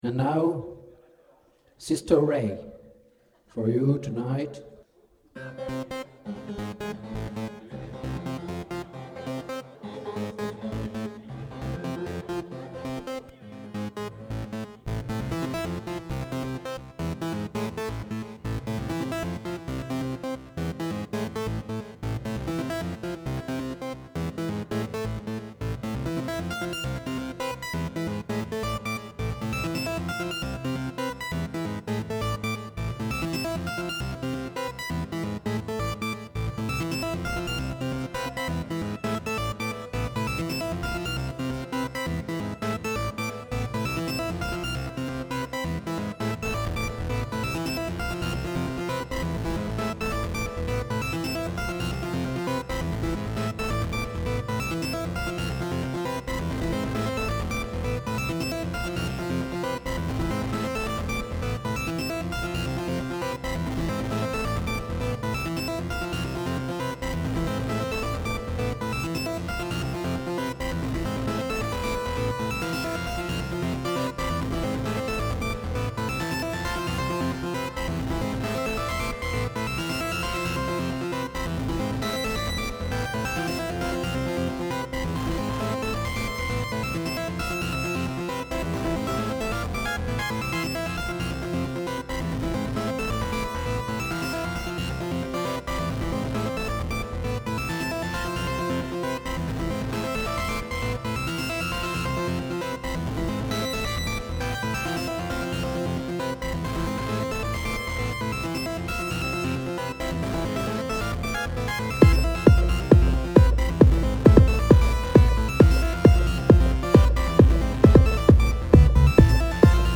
LIVE IN BLOCKAUS DY10